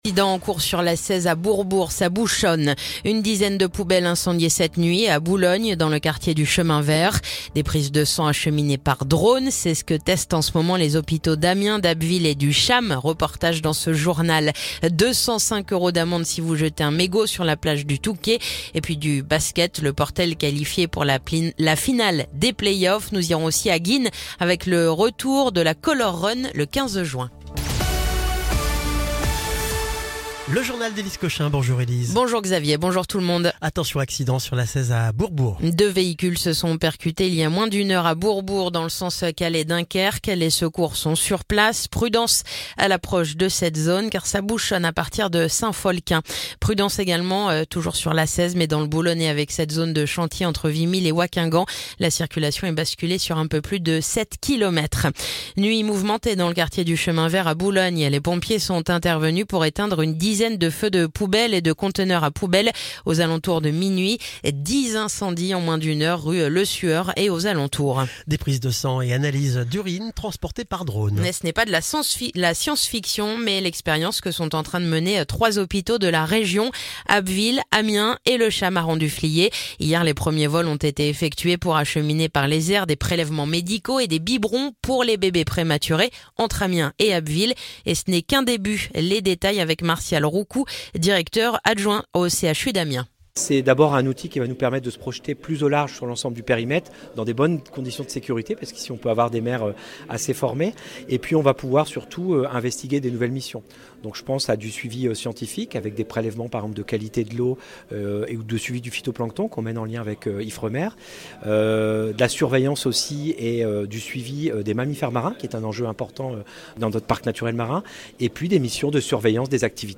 Le journal du mardi 3 juin